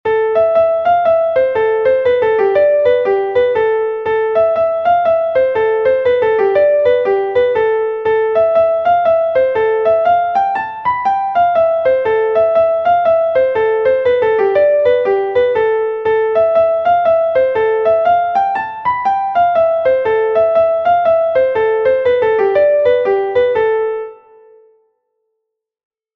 Gavotte from Brittany